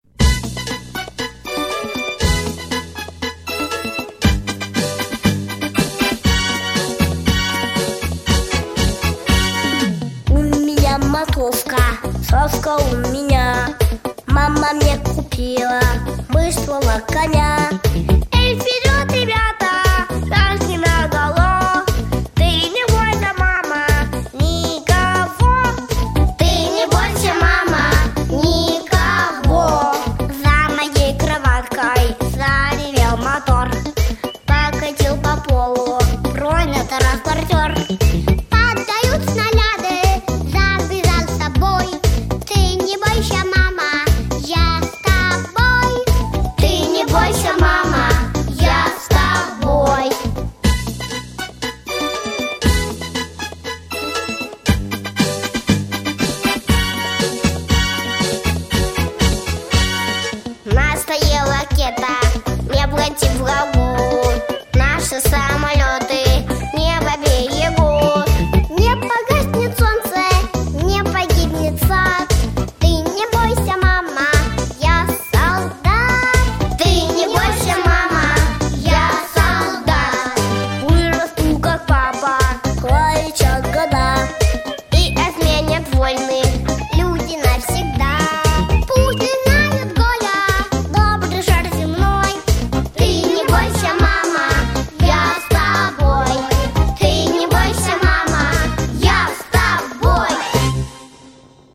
• Категория: Детские песни
военные песни